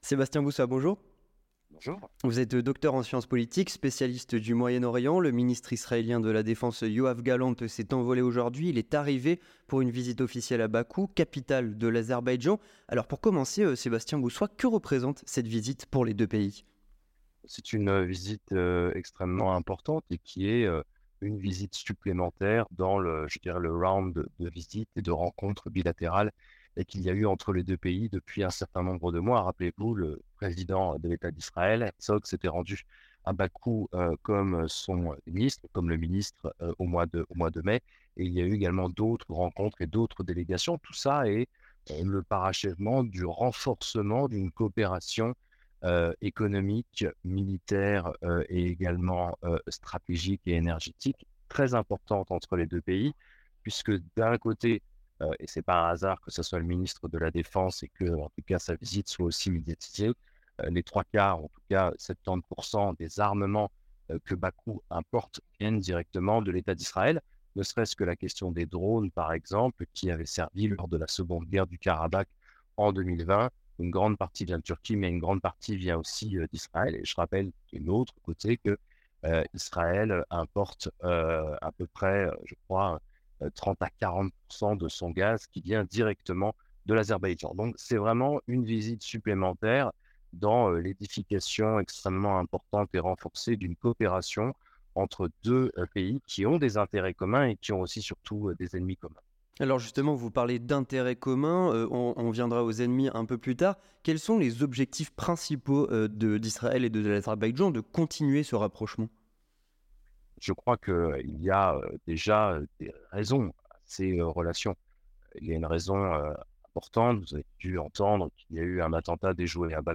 Entretien du 18h - Le voyage de Yoav Gallant en Azerbaïdjan